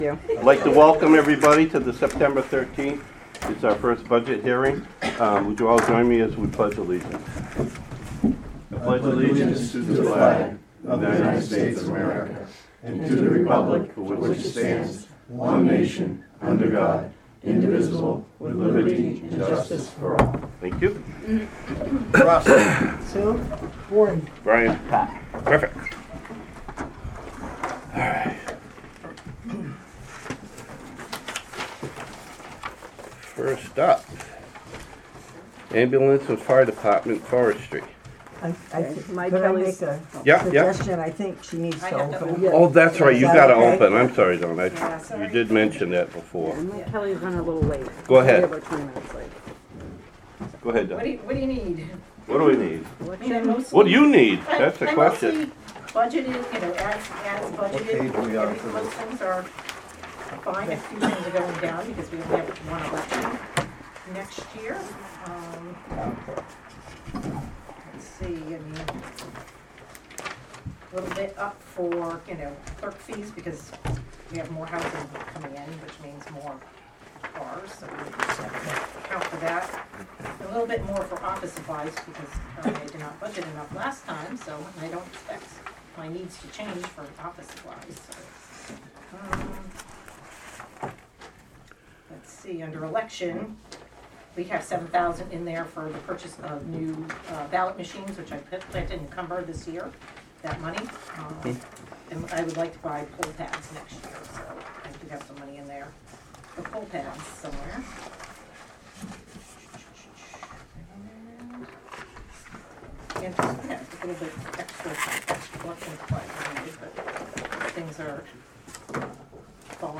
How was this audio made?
Board of Selectmen Meeting